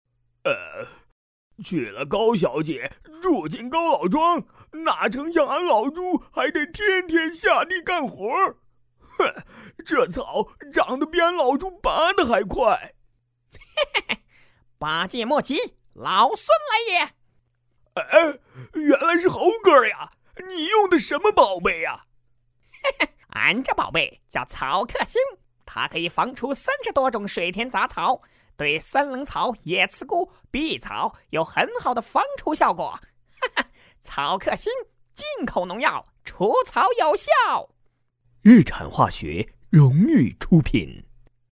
动画配音是对动画漫画人物、电影角色，用声音配合人物的面部表情以及情绪变化进行完美的声音演绎。
男声配音